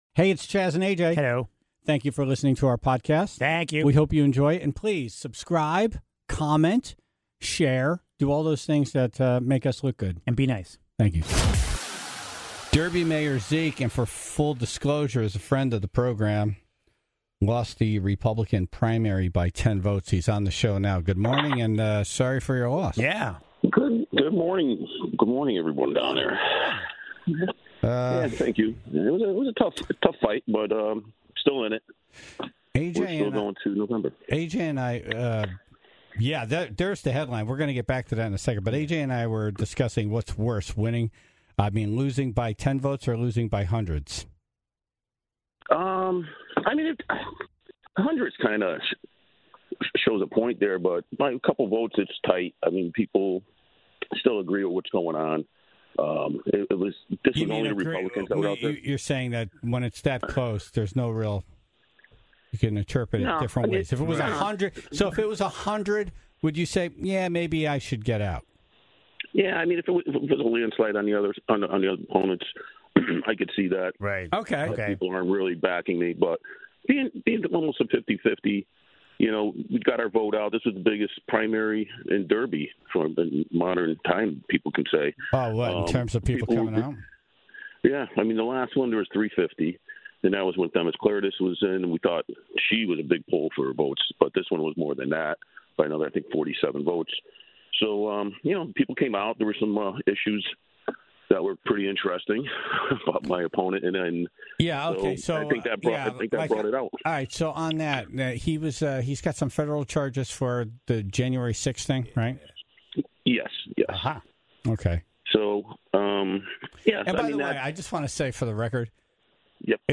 (8:11) The Tribe called in their pet injury stories, including the pug with a "lipstick" problem when he'd get too excited.